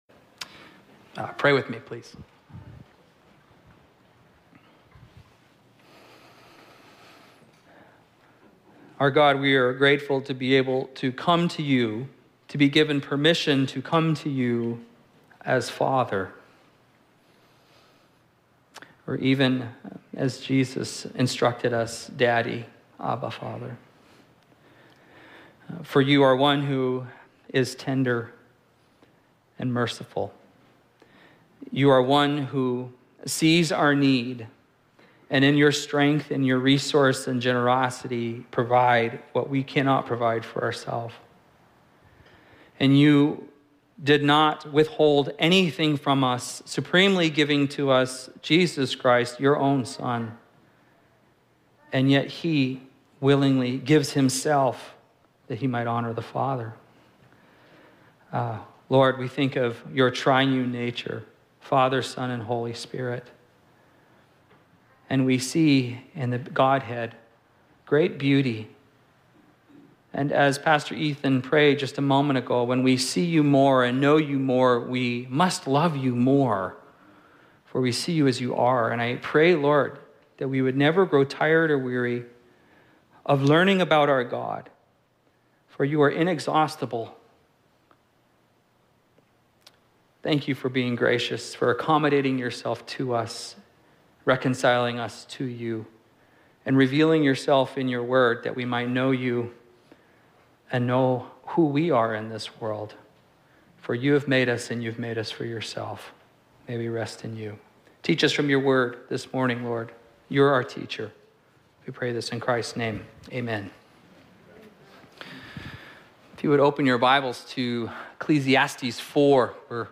Four Paths To Nowhere Bethel Church - Fairbanks, AK Sermons podcast